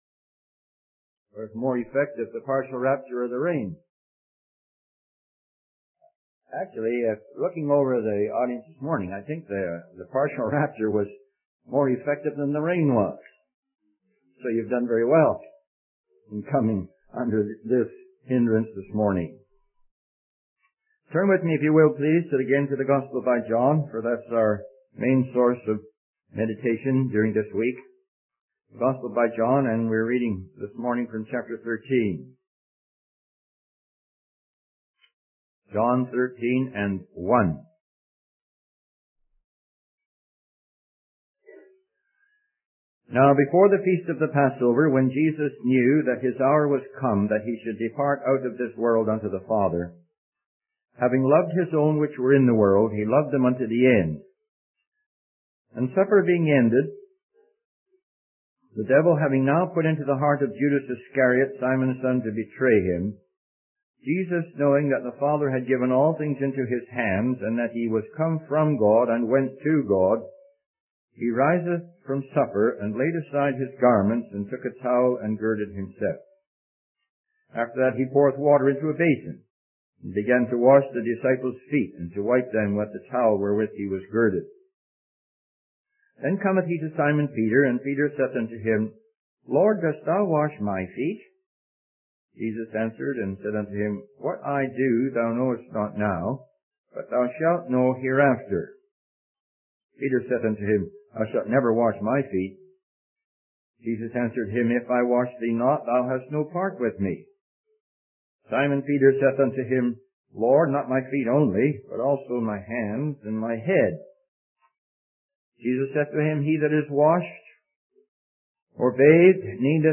In this sermon, the preacher begins by reflecting on previous teachings about Jesus being the living bread and the light of the world. He then moves on to discuss the humility of Jesus, focusing on the story of Jesus washing the disciples' feet.